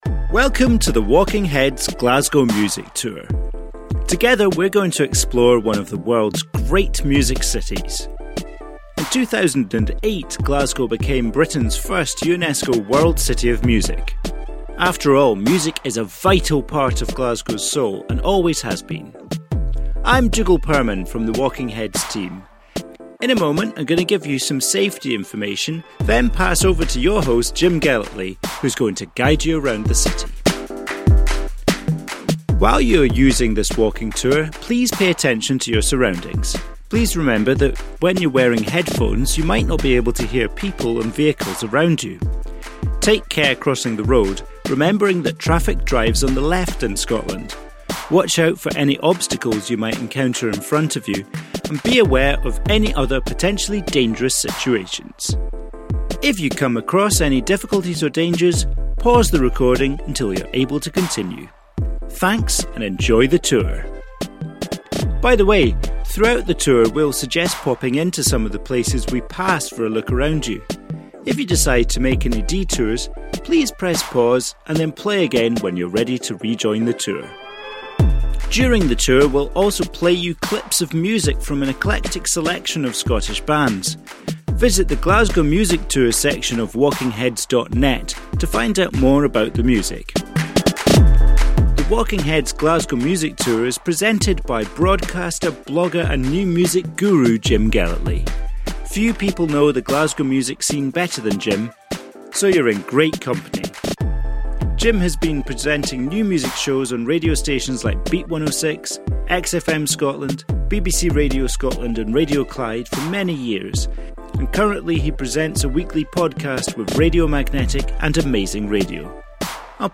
Tune in here and you will be walking to a soundtrack of locally inspired music.